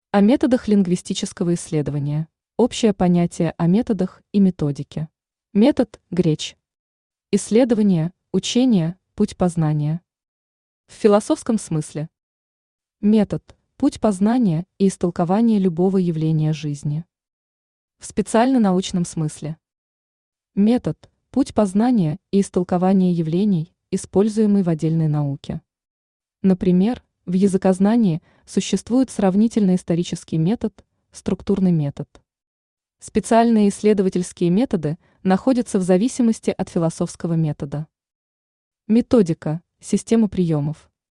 Аудиокнига Элементарно о методах изучения языка. Конспективное изложение | Библиотека аудиокниг
Конспективное изложение Автор Ирина Ивановна Тушева Читает аудиокнигу Авточтец ЛитРес.